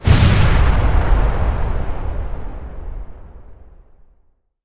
Звуки динамита
Звук на средней дистанции